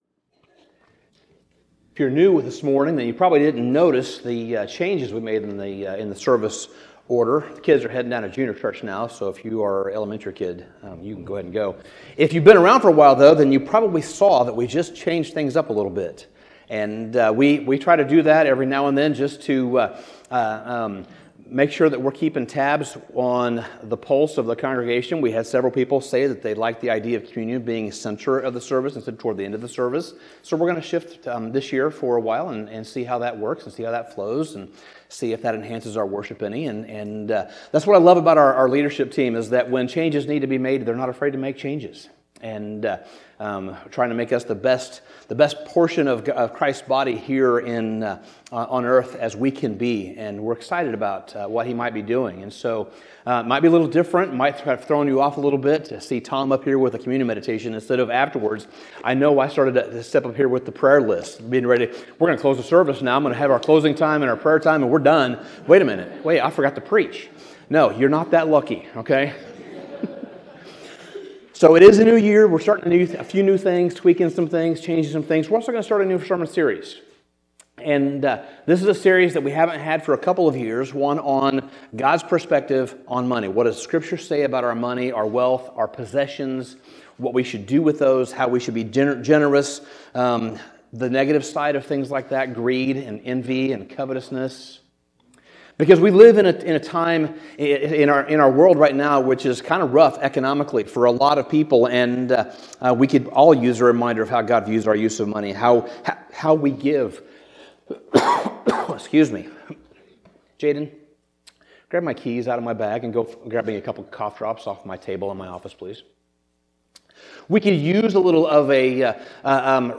Sermon Summary Jesus’ warning in Luke 12 is not aimed at wealthy people alone — it is aimed at anxious hearts. The rich fool was not condemned for having a good harvest, but for trusting his harvest instead of God.